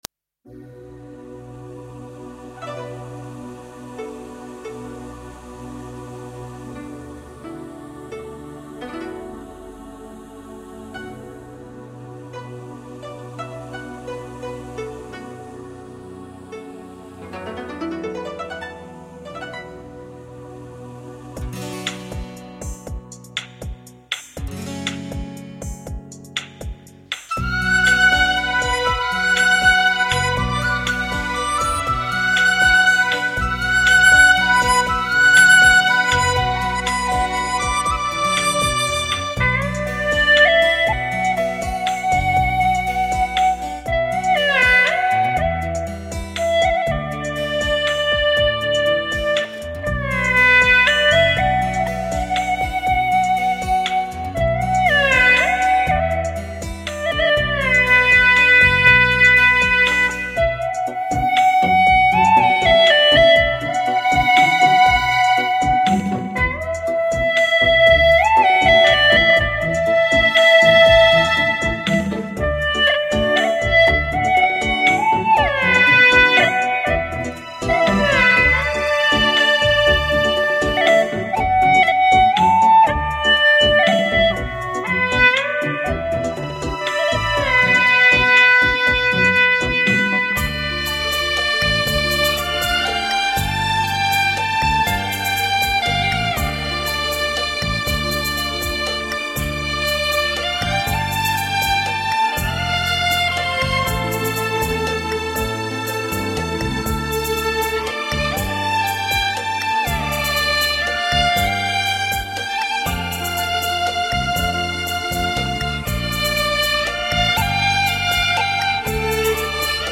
调式 : D 曲类 : 红歌